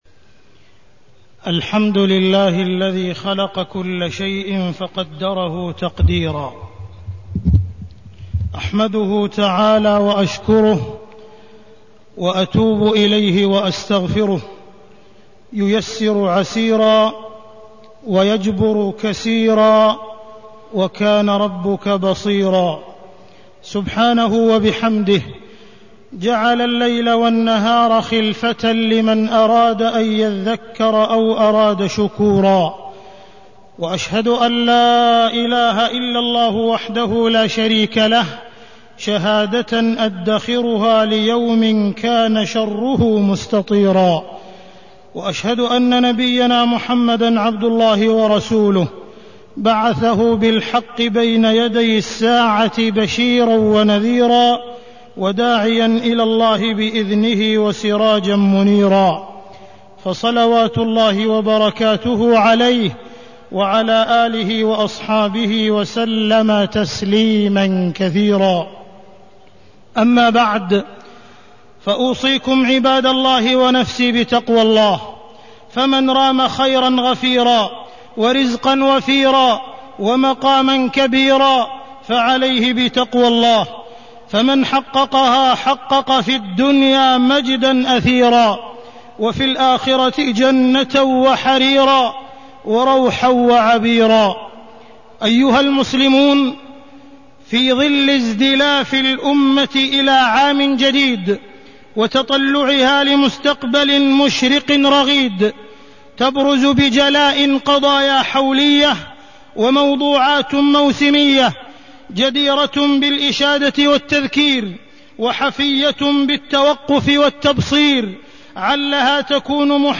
تاريخ النشر ٨ محرم ١٤٢٣ هـ المكان: المسجد الحرام الشيخ: معالي الشيخ أ.د. عبدالرحمن بن عبدالعزيز السديس معالي الشيخ أ.د. عبدالرحمن بن عبدالعزيز السديس استقبال العام الجديد